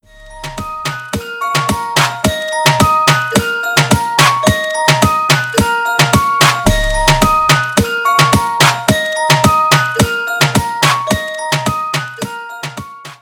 • Качество: 320, Stereo
Музыкальная шкатулка